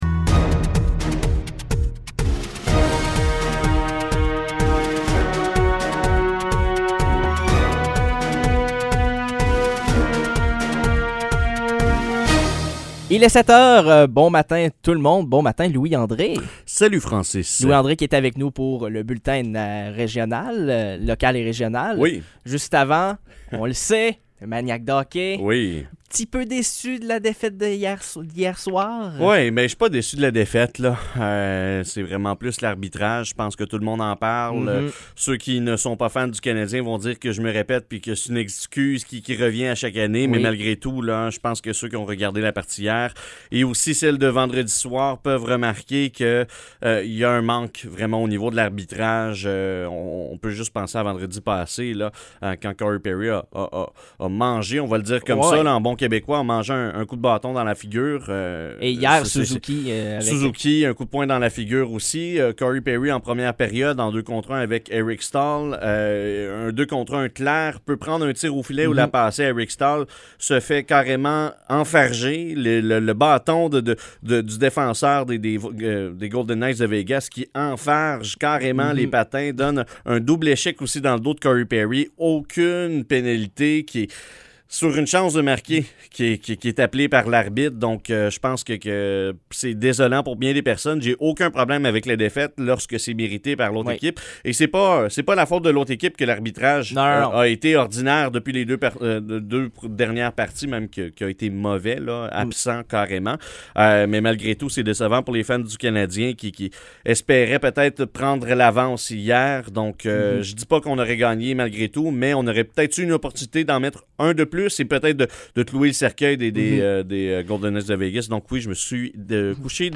Nouvelles locales - 21 juin 2021 - 7 h